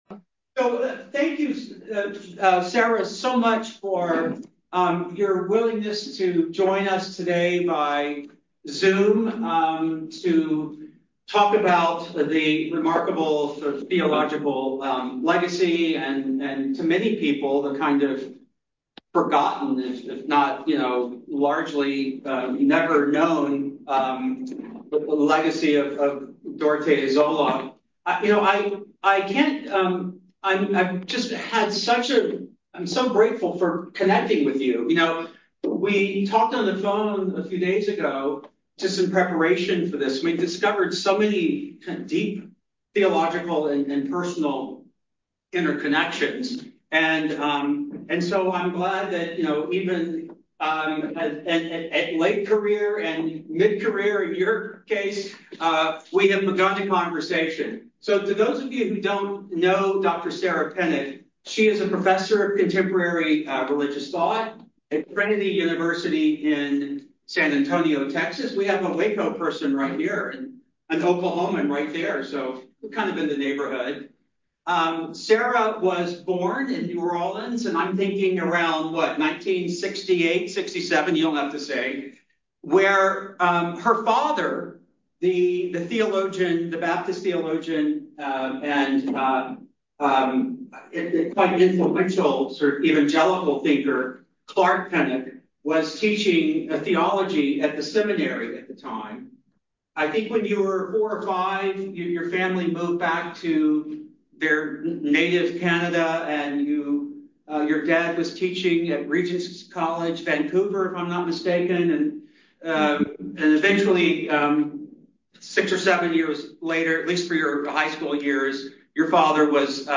Audio Information Date Recorded: November 19, 2024 Location Recorded: Charlottesville, VA Audio File: Download File » This audio is published by the Project on Lived Theology (PLT).